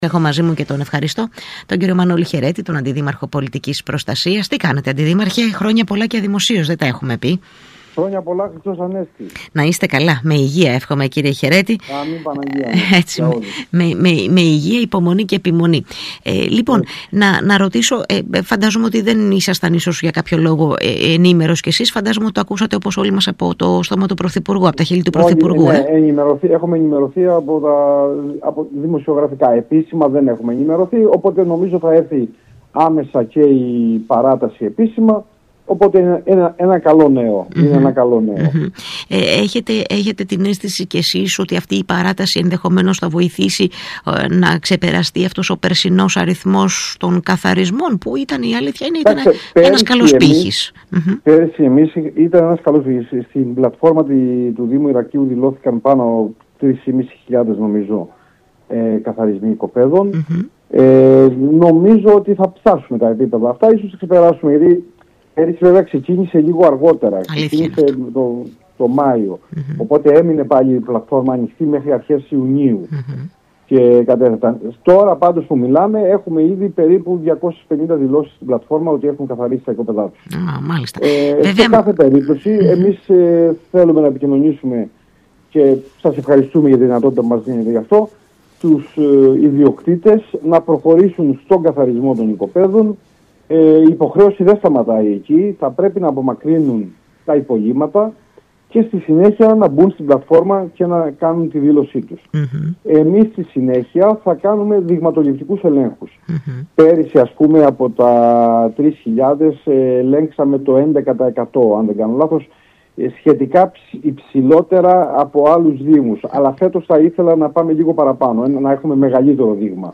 Πόσοι ιδιοκτήτες δήλωσαν μέχρι στιγμής ότι έχουν καθαρίσει τα οικόπεδά τους στο Ηράκλειο | O Αντιδήμαρχος Πολιτικής Προστασίας στον ΣΚΑΙ Κρήτης - CRETA24